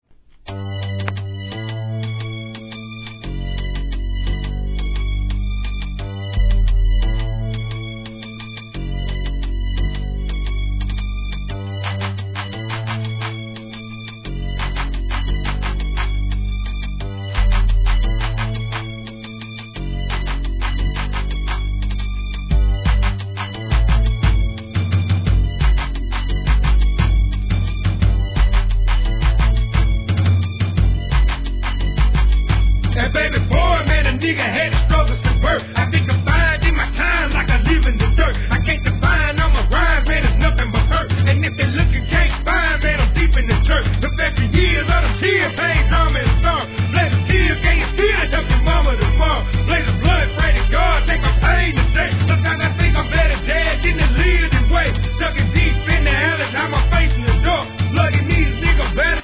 1. G-RAP/WEST COAST/SOUTH
切なささえ感じさせるようなシンセのLOOPであしらったトラックで披露する、SOUTH直球の超ドマイナーRAP!!!